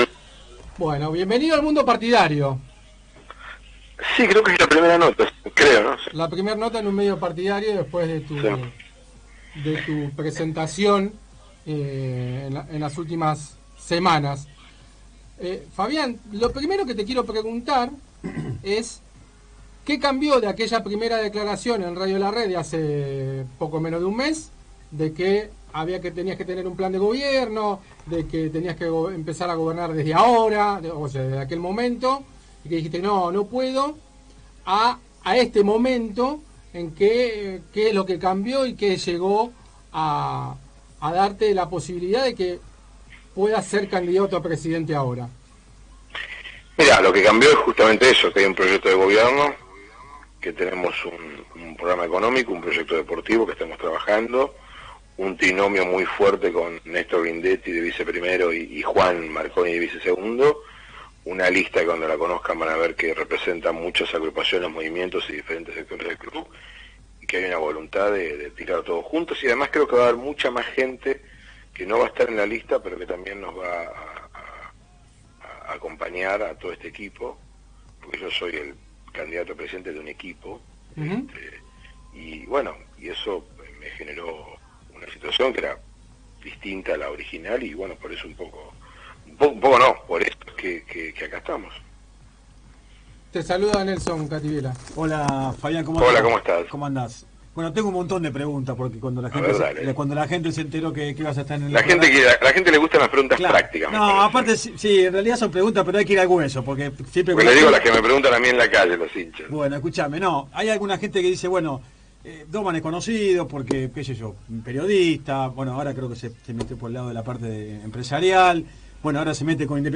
El candidato a presidente por el frente Unidad Independiente Fabián Doman habló en MasRojo Radio En el inicio explicó porque cambió de opinión y volver a ser candidato cuando había decidido no serl…